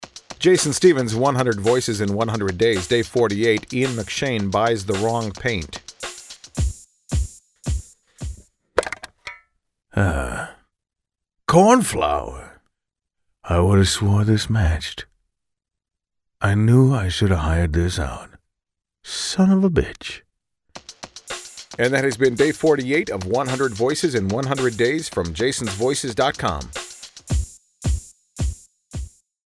On that list of favorites, my Ian McShane impression.
Tags: 100 celebrity voices, celebrity voice impressions, Ian McShane impression